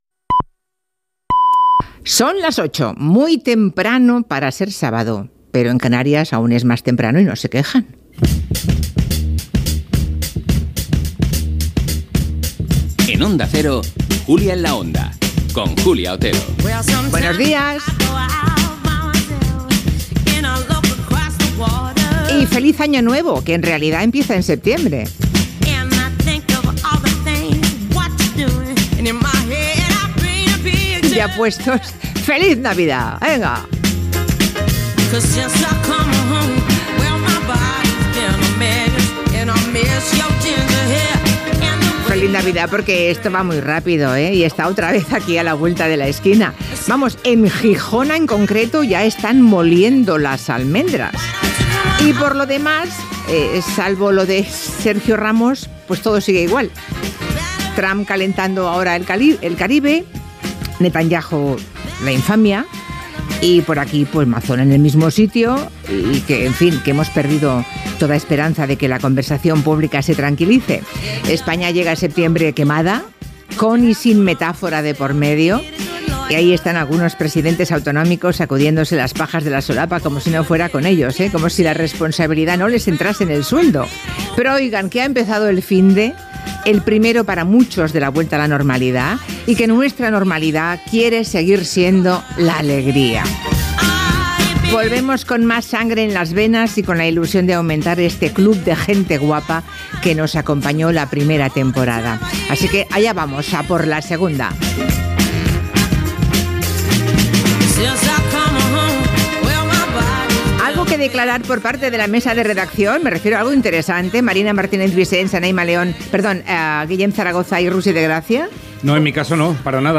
Hora, salutació, careta, presentació i salutació als components de la "Mesa de redacción". Sumari i tema musical.
Entreteniment